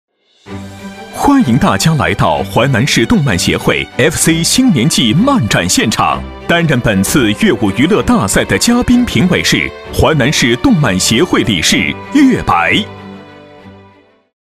男40-开场介绍【漫展-用力大气】
男40-开场介绍【漫展-用力大气】.mp3